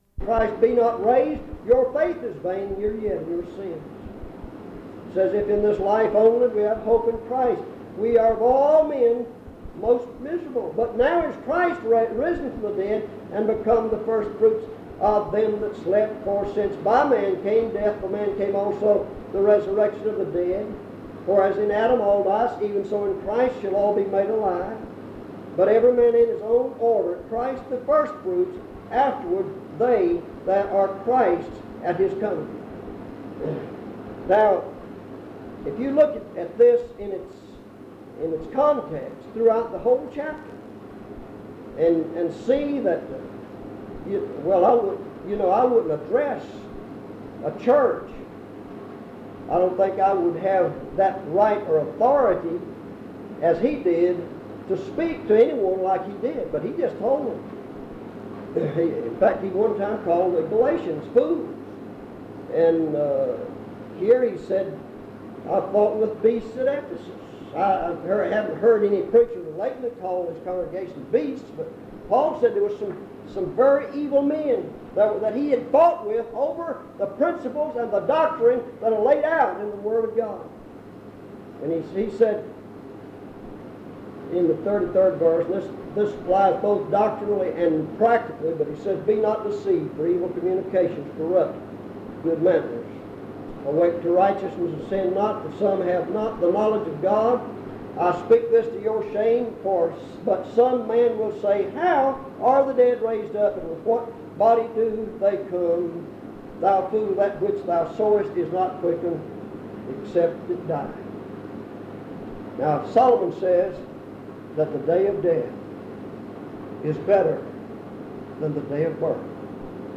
Matthew Language English Identifier PBHLA-ACC.004_009-A-01/PBHLA-ACC.004_009-B-01 Date created 1998-09-06 Location Browns Summit (N.C.) Guilford County (N.C.)
En Collection: Primitive Baptist churches audio recordings Miniatura Título Fecha de subida Visibilidad Acciones PBHLA-ACC.004_009-A-01.wav 2026-02-12 Descargar PBHLA-ACC.004_009-B-01.wav 2026-02-12 Descargar